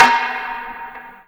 prcTTE44034tom.wav